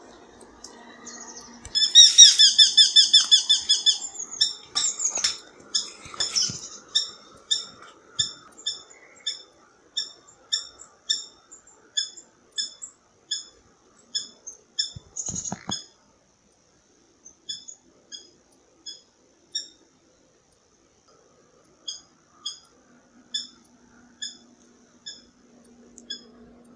eurasian-hobby-call
Eurasian-Hobby-Falco-subbuteo-1-1.mp3